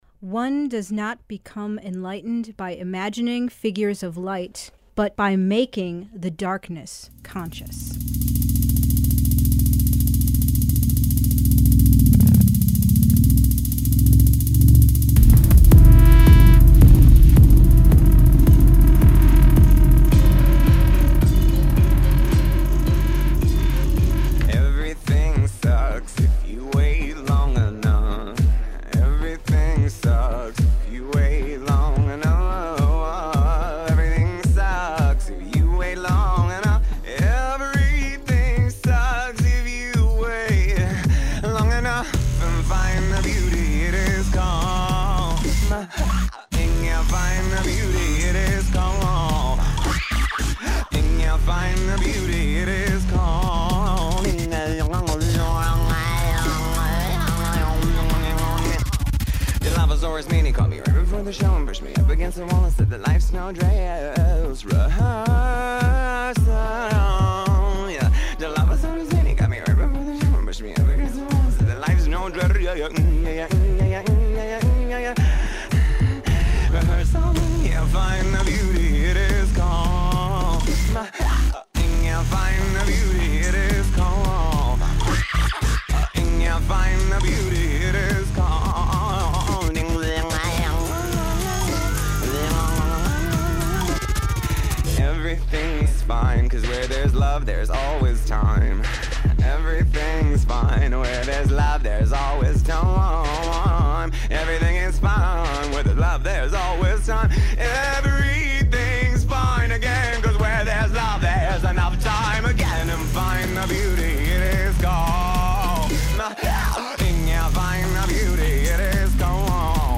Radio interview about Bipolar Bitch.